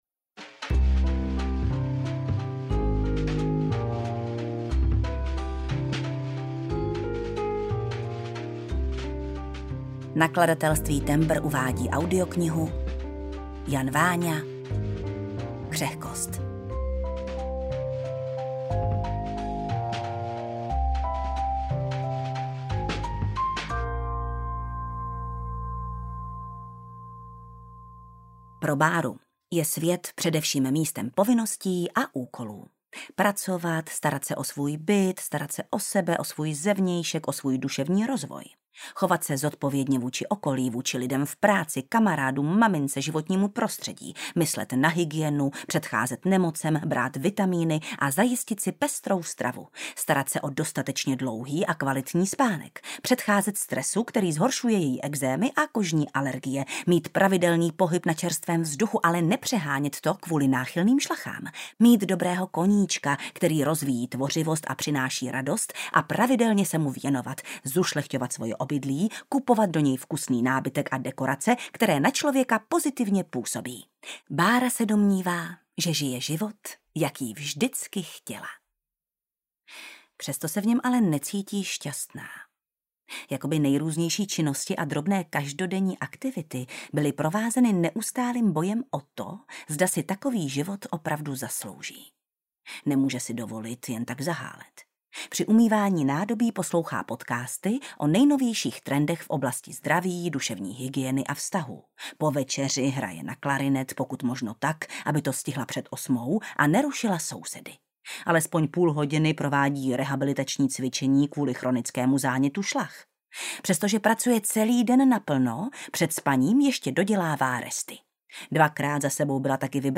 Křehkost audiokniha
Ukázka z knihy
• InterpretJana Stryková